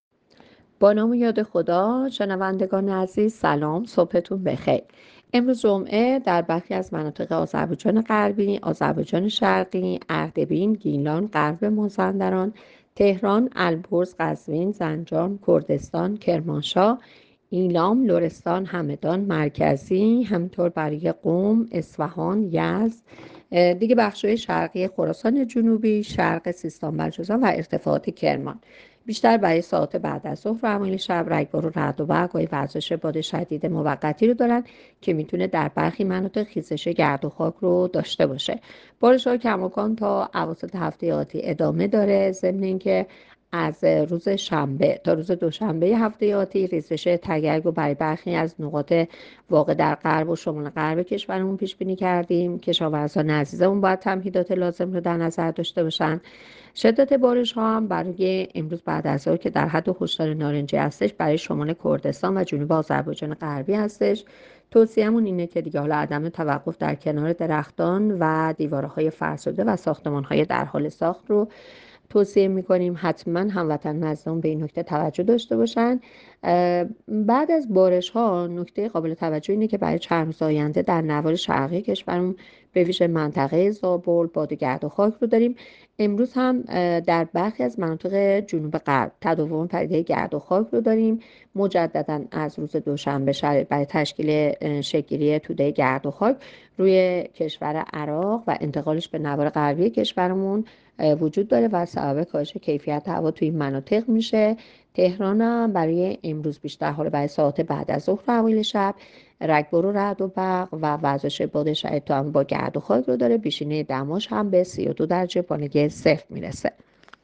گزارش رادیو اینترنتی پایگاه‌ خبری از آخرین وضعیت آب‌وهوای دوازدهم اردیبهشت؛